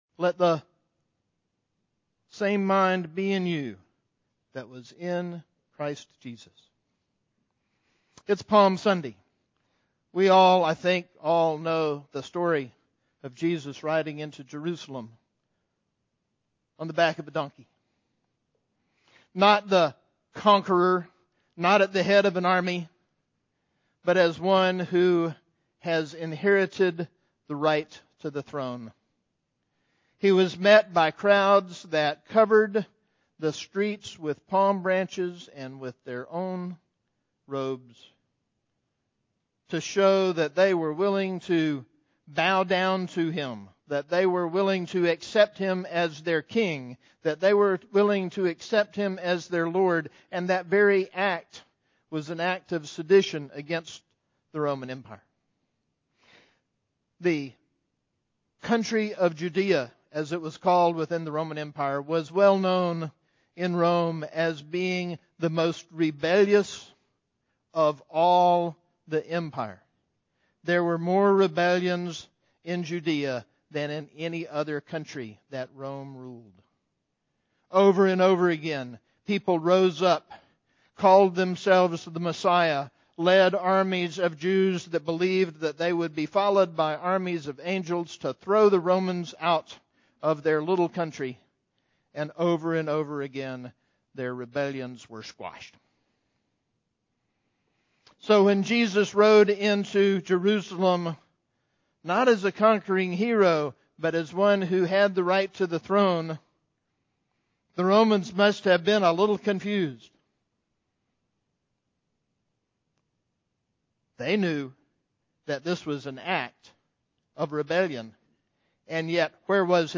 Sermon: The Mind of Christ
20 - Easter Sunday